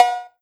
Drums
EXcow.wav